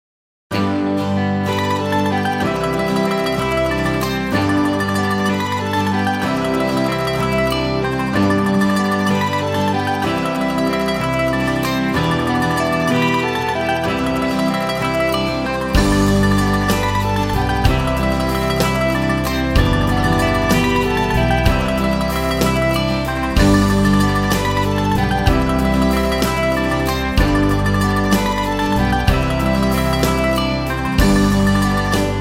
פלייבק איכותי – תואם מקור